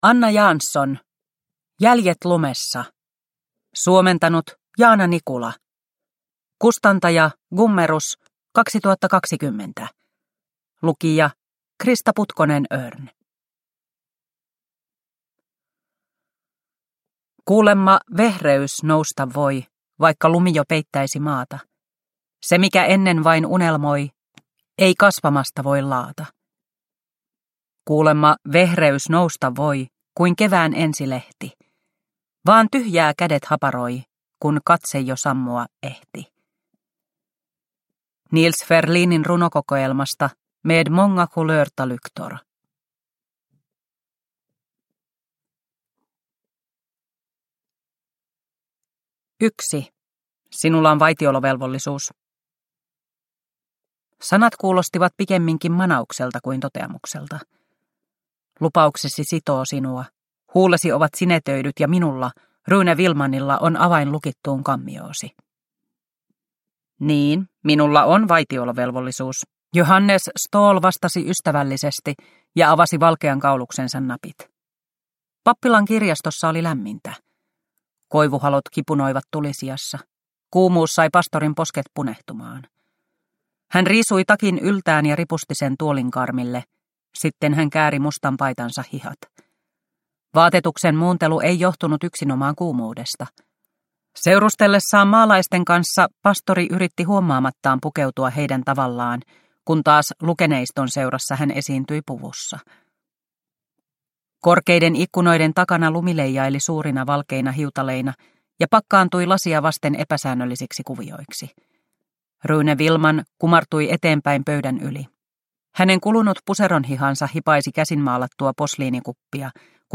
Jäljet lumessa – Ljudbok – Laddas ner